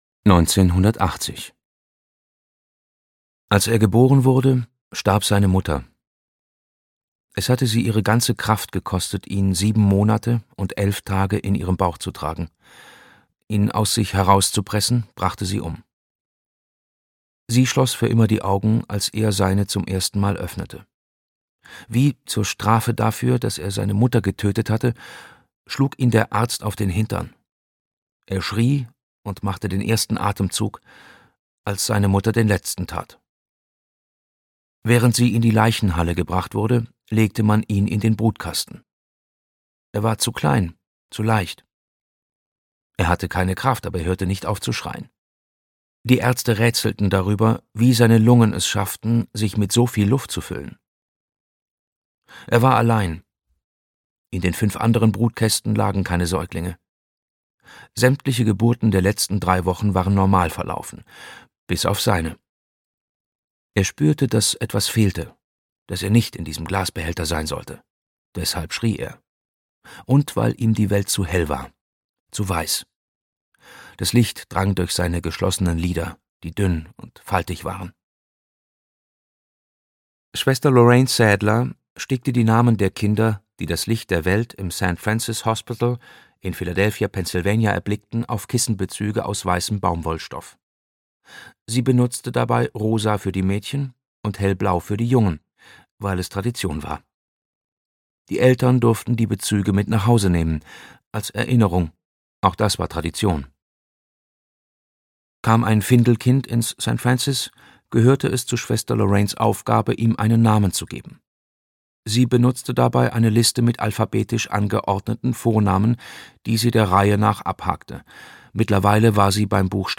Nach Hause schwimmen - Rolf Lappert - Hörbuch